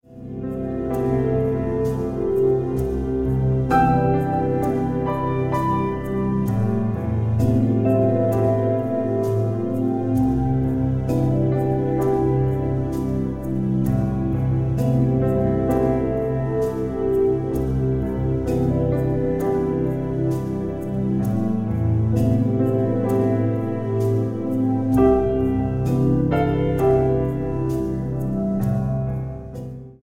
66 BPM